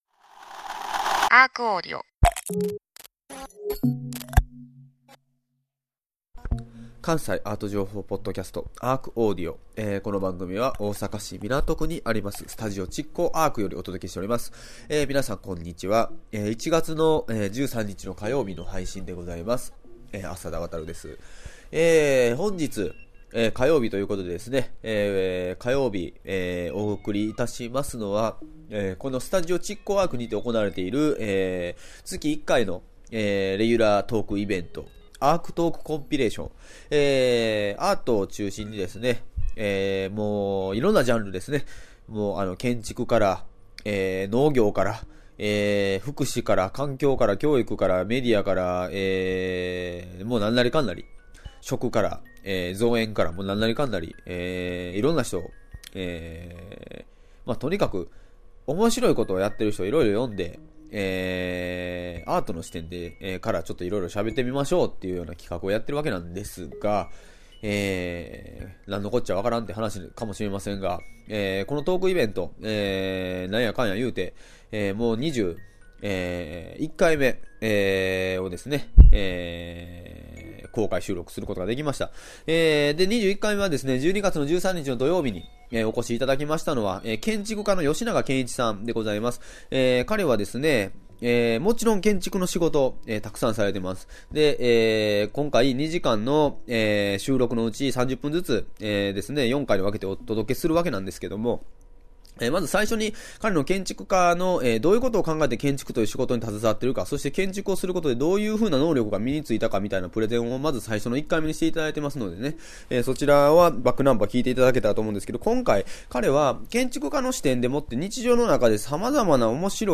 1/13(火) ARCAudio!! トーク「けんちくに学ぶ、日常の面白さの、見つけ方と伝え方」2/4
毎週火曜日は築港ARCにて毎月開催されるアートと社会を繋ぐトークイベント「ARCトークコンピレーション」の模様を全4回に分けてお届けします。今月のテーマはずばり！「建築から見つめる日常」。